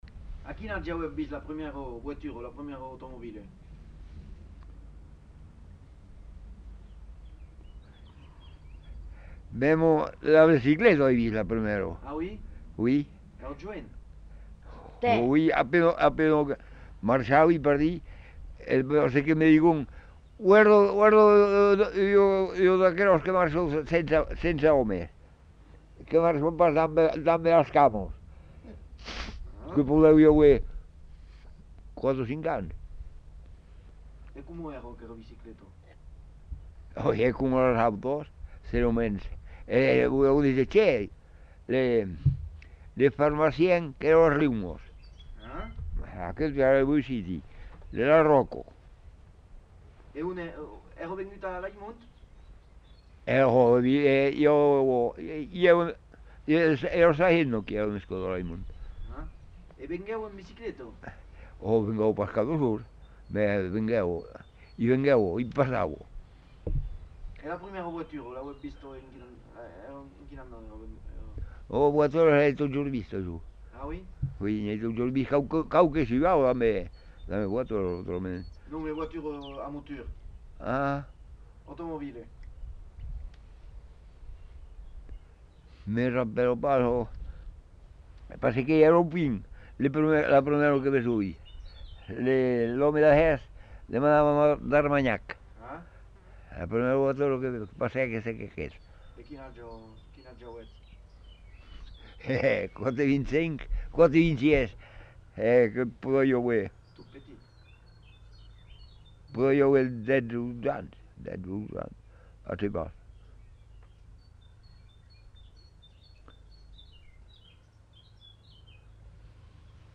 Lieu : Montadet
Genre : témoignage thématique